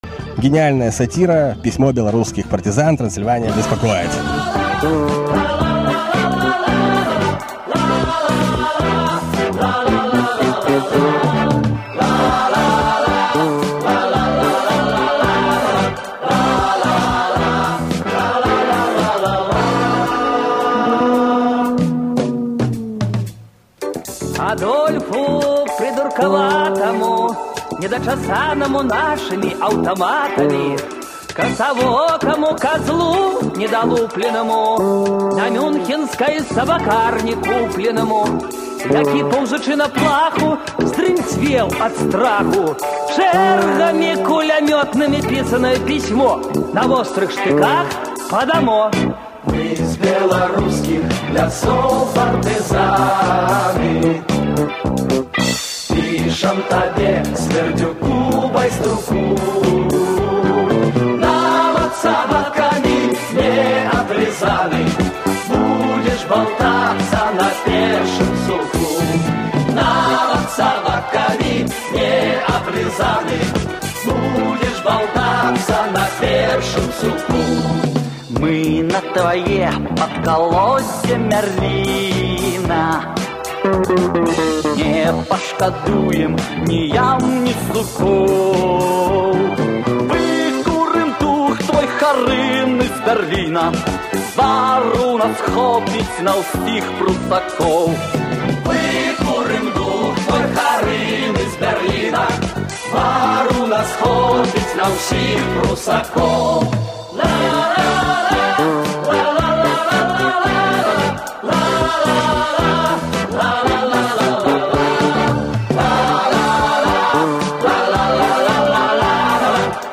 где и когда выпускалась эта сатирическая песня
Первый солист